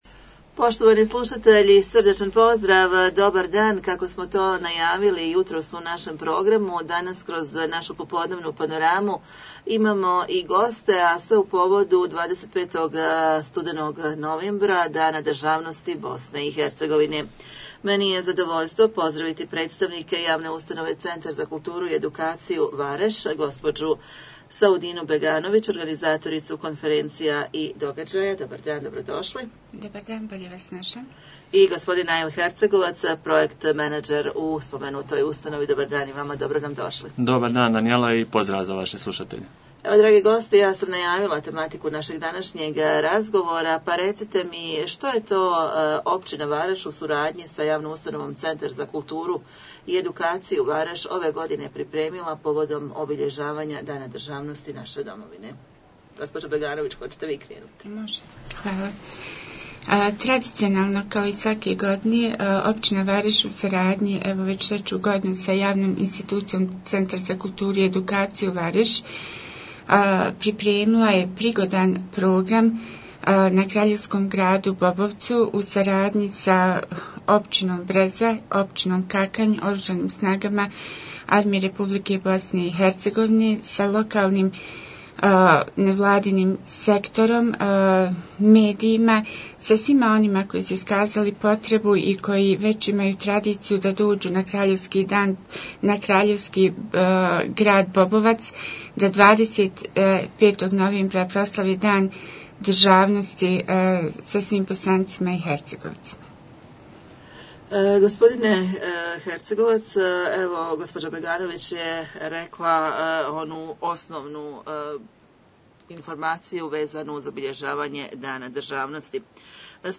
Gosti u studiju